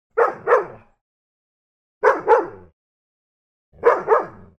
دانلود آهنگ پارس کردن سگ 2 بار پشت سر هم از افکت صوتی انسان و موجودات زنده
دانلود صدای پارس کردن سگ 2 بار پشت سر هم از ساعد نیوز با لینک مستقیم و کیفیت بالا
جلوه های صوتی